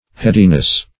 Headiness \Head"i*ness\, n. The quality of being heady.
headiness.mp3